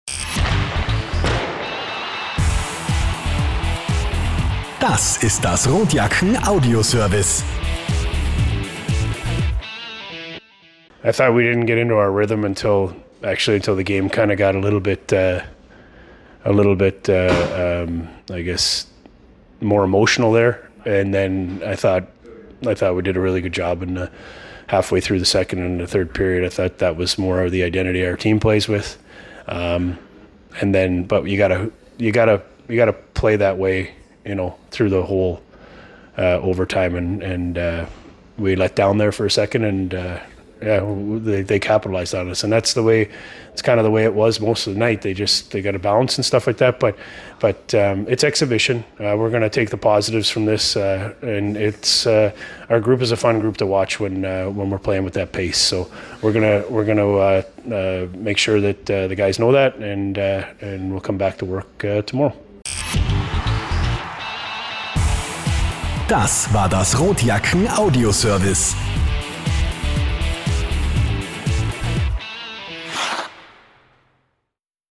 Post Game-Kommentar